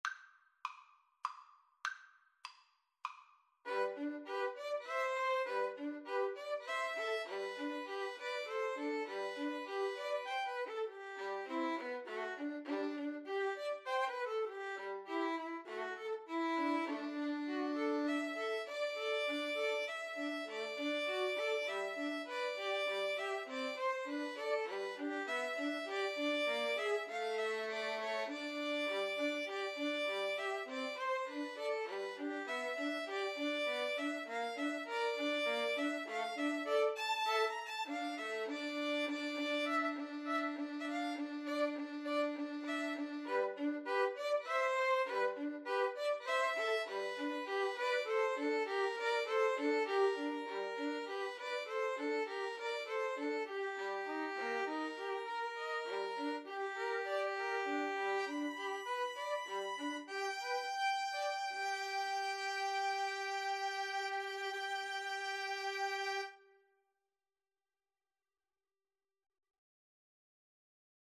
G major (Sounding Pitch) (View more G major Music for Violin Trio )
3/4 (View more 3/4 Music)
~ = 100 Allegretto grazioso (quasi Andantino) (View more music marked Andantino)
Violin Trio  (View more Intermediate Violin Trio Music)
Classical (View more Classical Violin Trio Music)